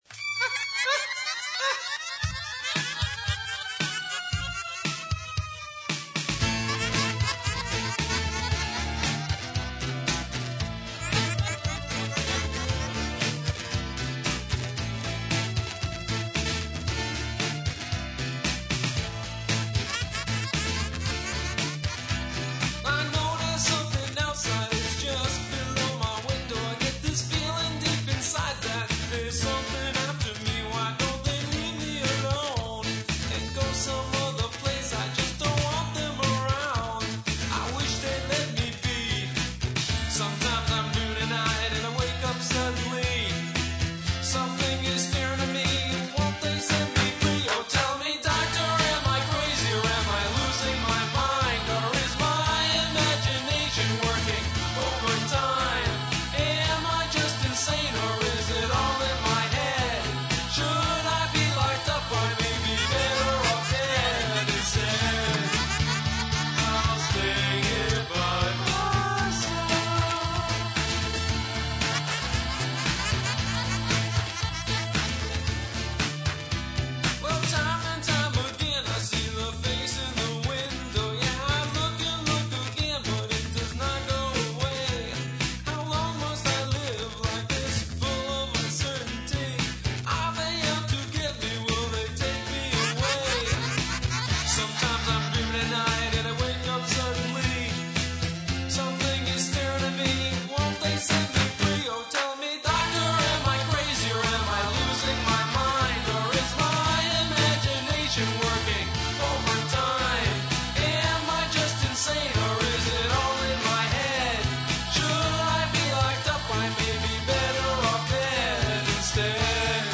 This one was truly a solo project with yours truly responsible for Words, Music, Programming, guitars and vocals.